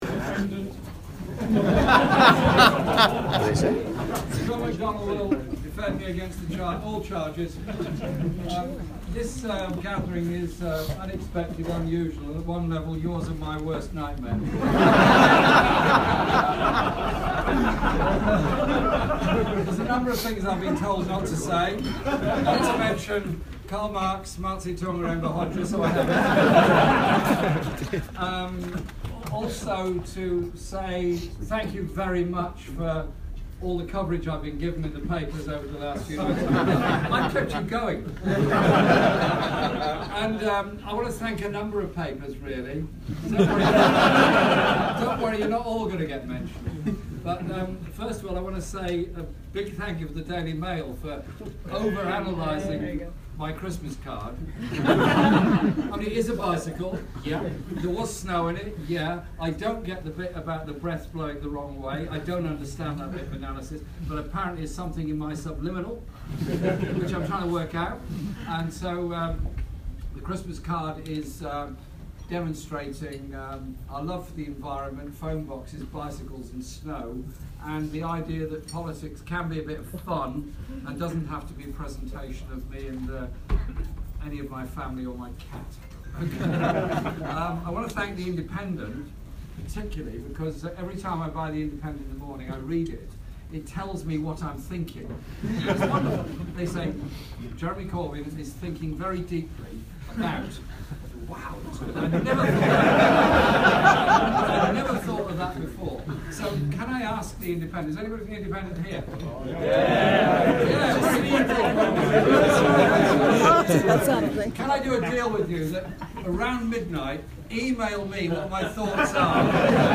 Corbyn speech at journalists' drinks